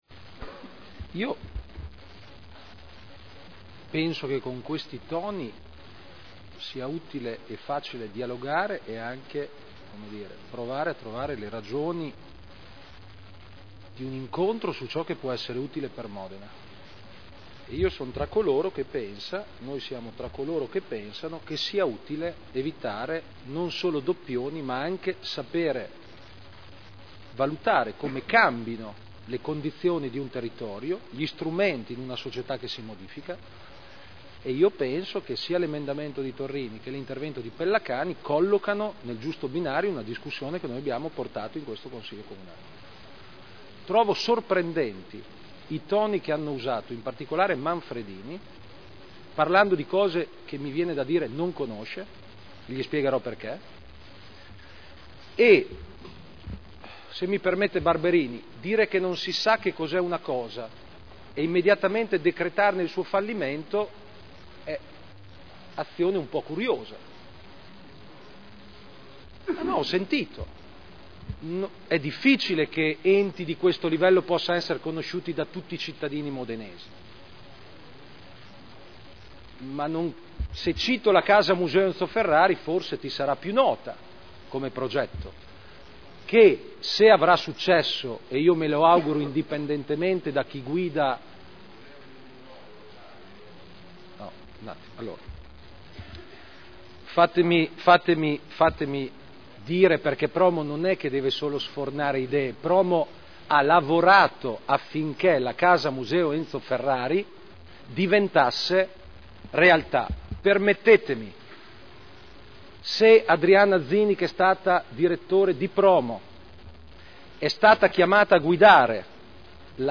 Seduta del 10/12/2009. Ridefinizione della missione della compagine societaria di PROMO S.c.a.r.l. – Vendita alla C.I.A.A. di Modena di parte della quota societaria e di un’area limitrofa alla sede (Commissione consiliare del 26 novembre 2009) – Presentato emendamento prot. 151289 in data 30.11.2009